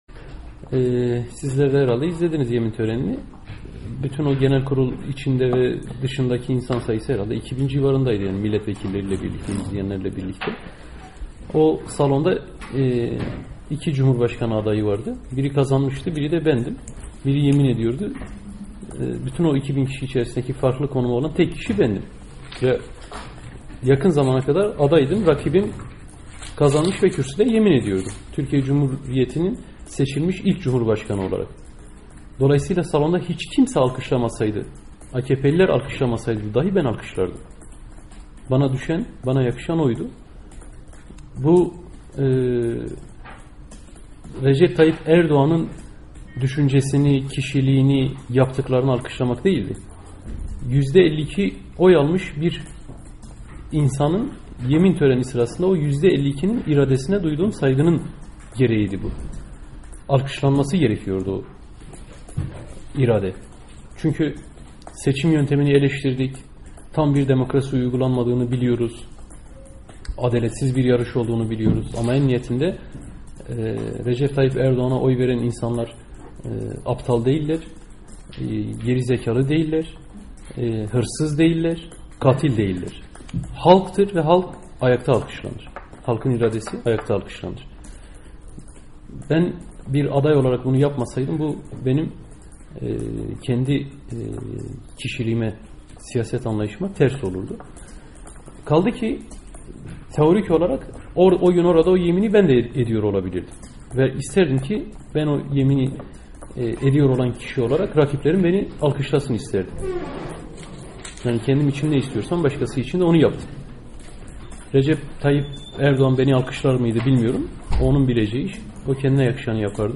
Selahattin Demirtaş'la Söyleşi